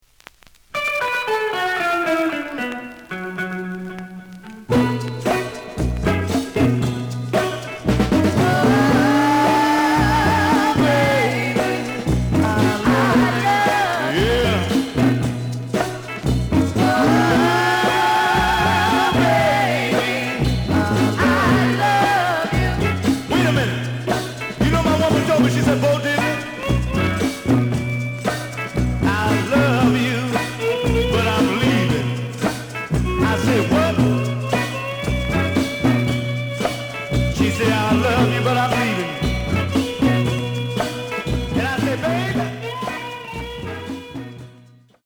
The audio sample is recorded from the actual item.
●Genre: Rhythm And Blues / Rock 'n' Roll
Some noise on A side.